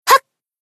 贡献 ） 分类:蔚蓝档案语音 协议:Copyright 您不可以覆盖此文件。
BA_V_Miyako_Battle_Shout_2.ogg